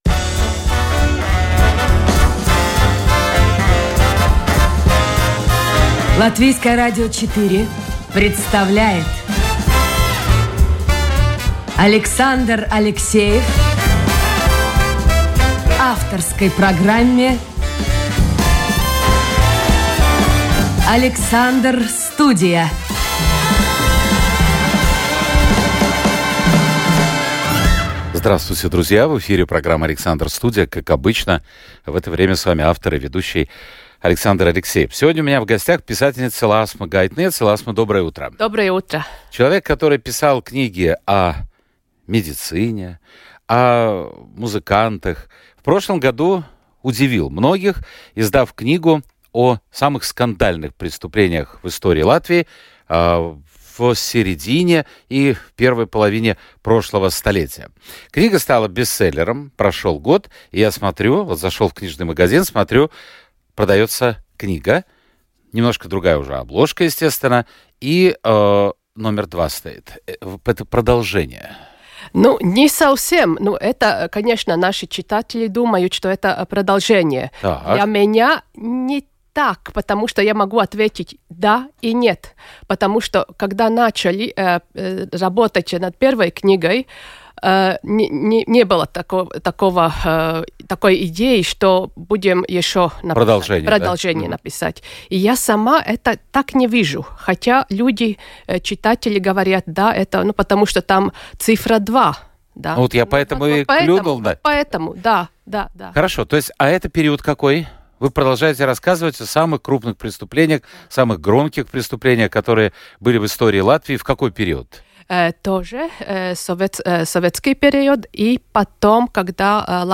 Живой и непринужденный диалог со слушателями, неформальный разговор с известными людьми, ТОК-ШОУ с участием приглашенных экспертов о самых невероятных явлениях нашей жизни.